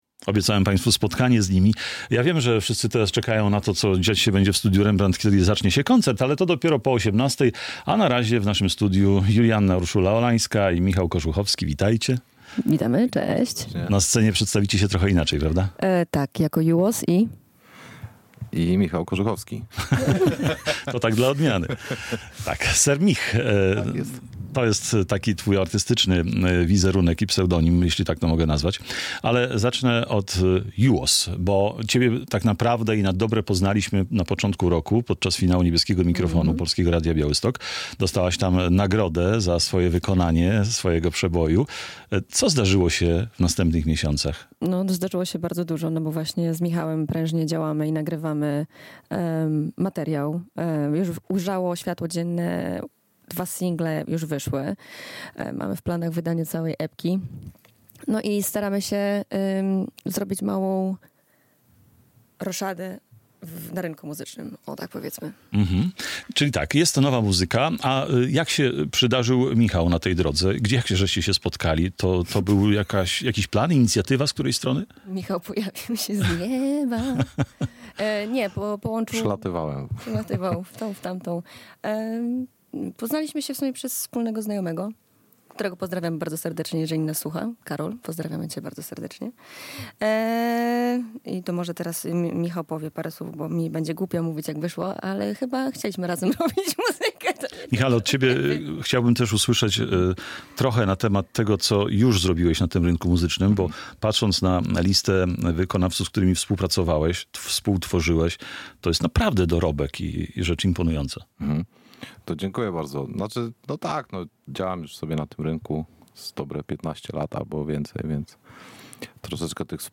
Przed koncertem w Polskim Radiu Białystok